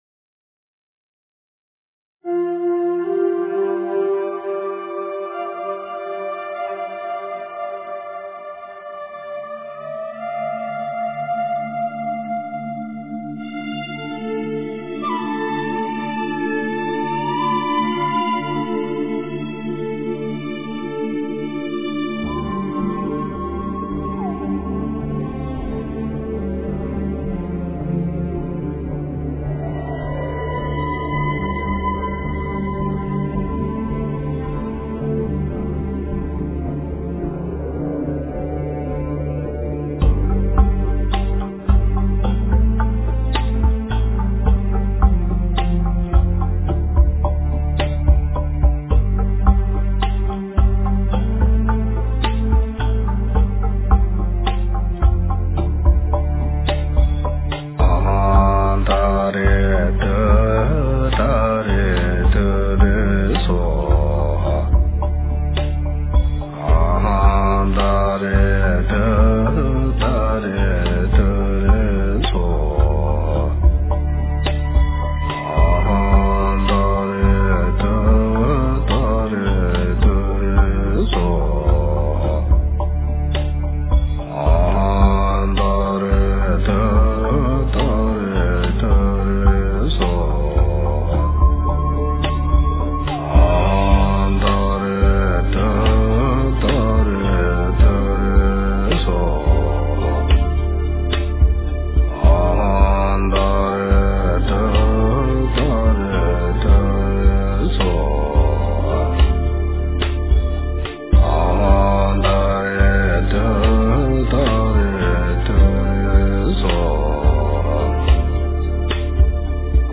佛音 真言 佛教音乐 返回列表 上一篇： 南无佛陀耶-南无达摩耶-南无僧伽耶--佛教音乐 下一篇： 楞严咒--佚名 相关文章 五指的争议--有声佛书 五指的争议--有声佛书...